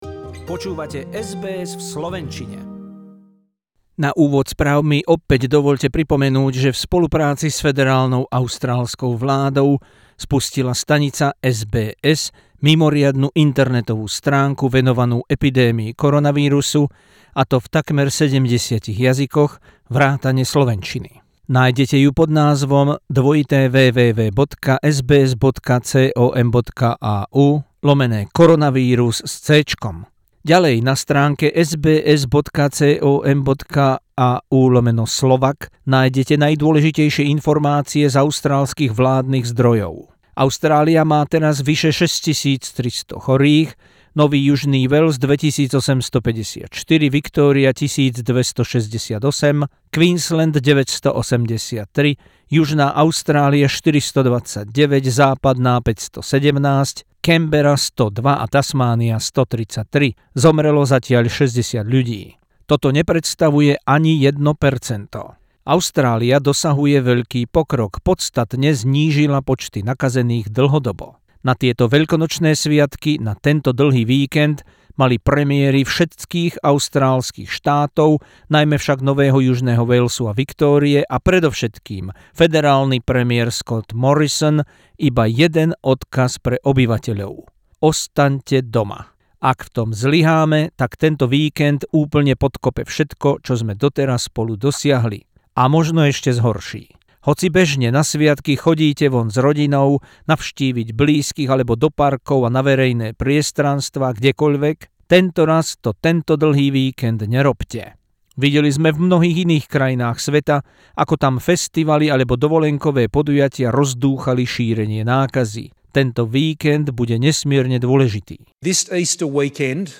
News bulletin in Slovak language on SBS Radio Australia from Sunday 12th April 2020, including extensive coverage of COVID-19.